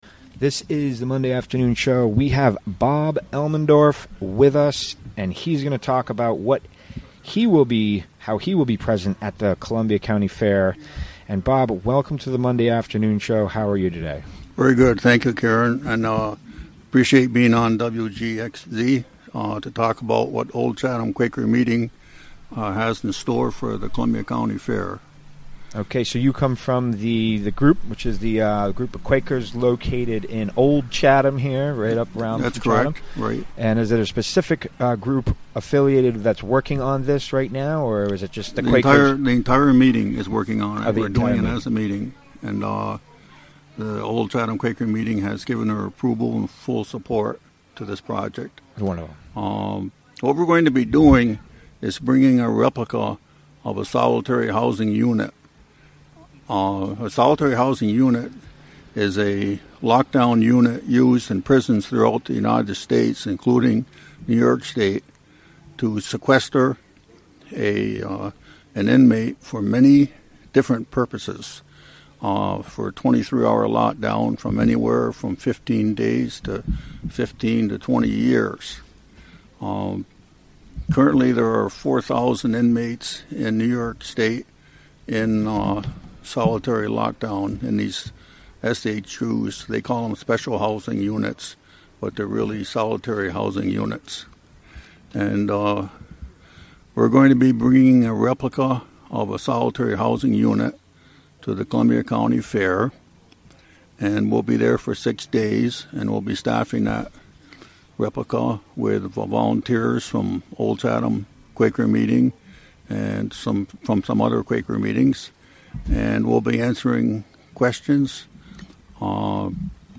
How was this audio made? Recorded during the WGXC Afternoon Show Monday, August 21, 2017.